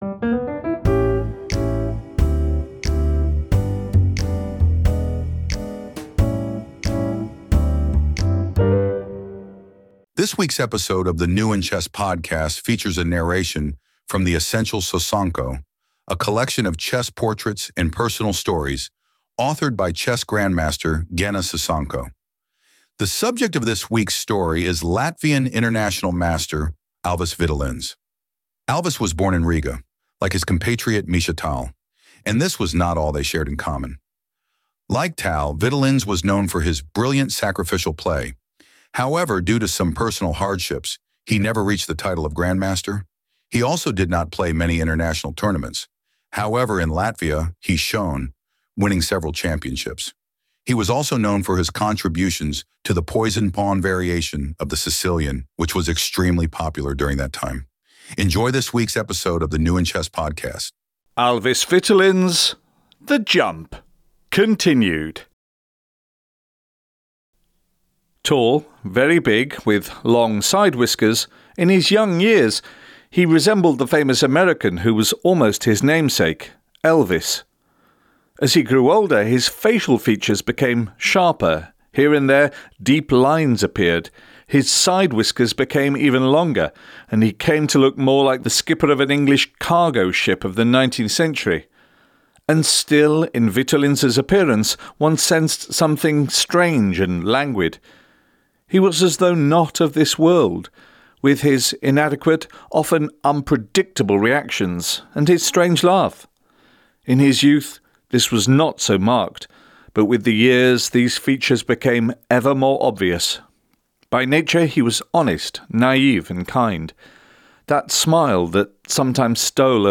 This week's episode of the New In Chess Podcast features a narration from the Essential Sosonko, a collection of chess portraits and personal stories authored by chess grandmaster Genna Sosonko. The subject of this week's story is Latvian international master Alvis Vitolins.